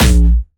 Jumpstyle Kick 3
6 A#1.wav